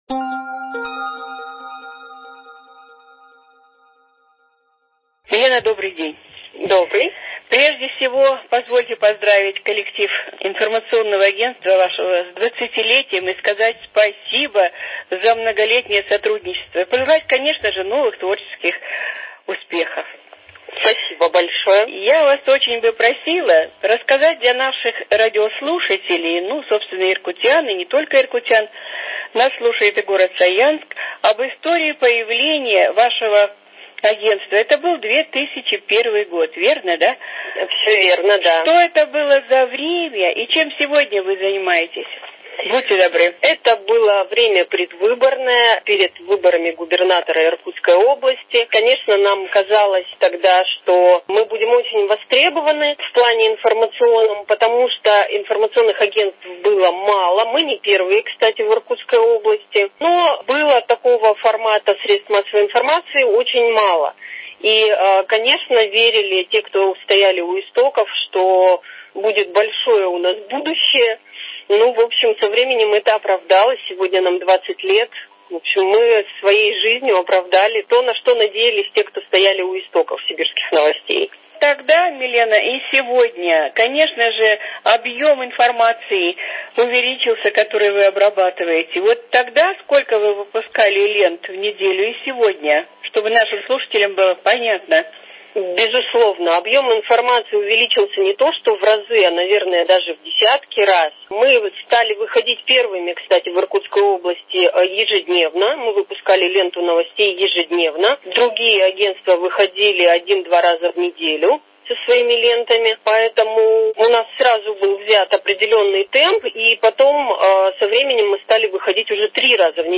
Актуальное интервью по телефону: 20 лет информационному агентству «Сибирские новости» 19.04.2021